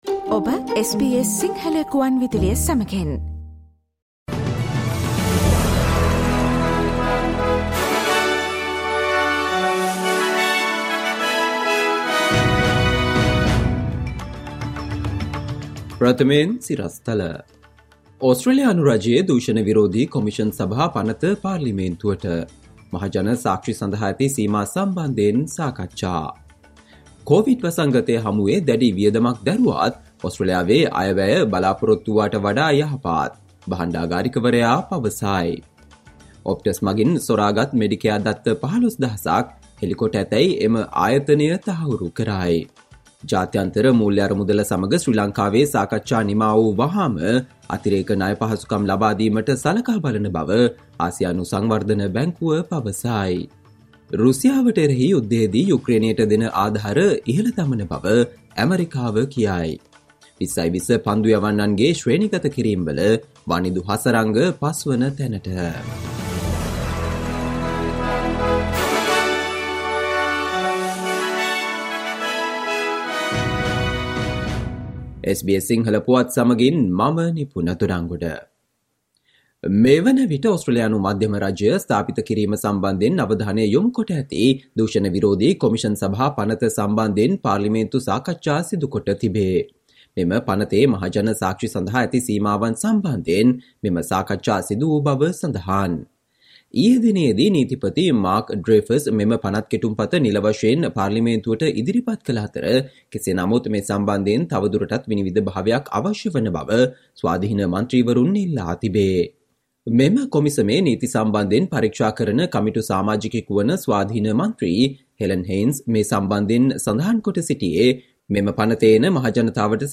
Listen to the SBS Sinhala Radio news bulletin on Thursday 29 September 2022